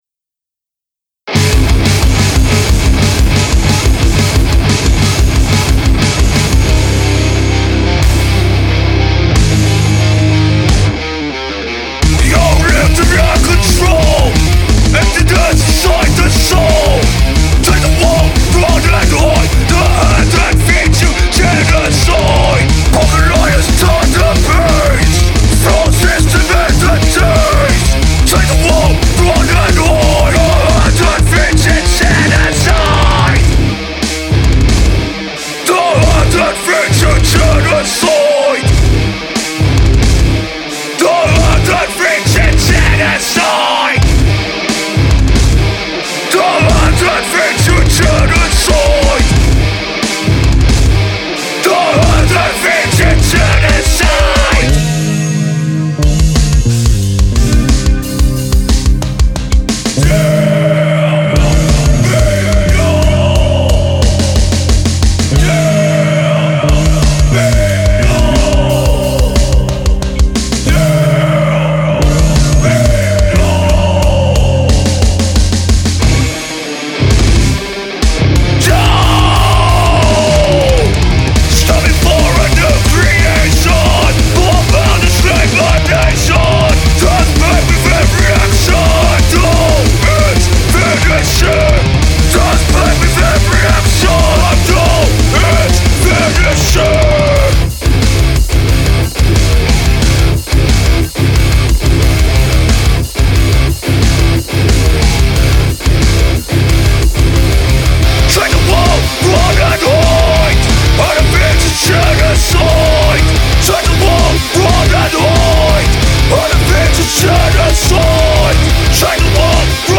Here is our latest song that i have recorded and mixed (attached). This is only my 3rd ever attempt at mixing, and am quite happy with sound of it.